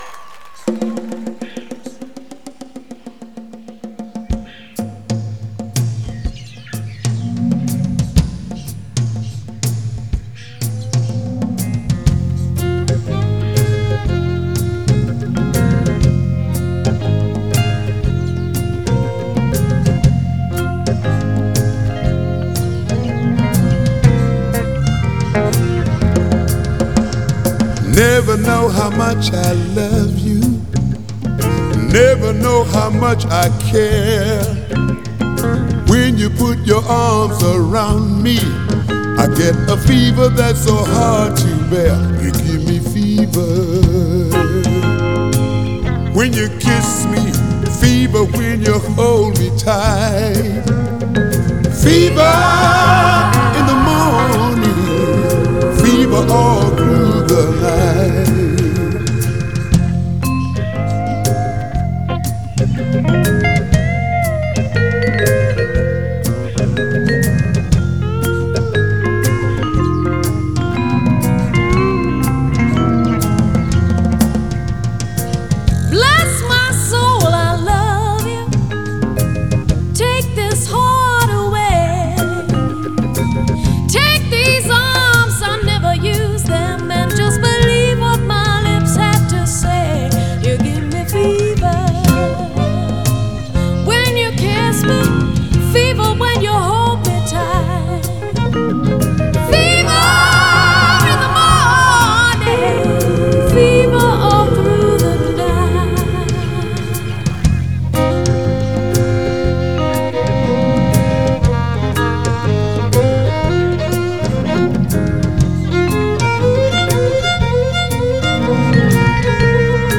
앨범은 재즈 , 블루스 및 소울 표준으로 구성되며 솔로와 듀엣이 혼합되어 있습니다.